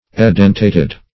Edentated \E*den`ta*ted\ ([-e]*d[e^]n"t[asl]*t[e^]d), a.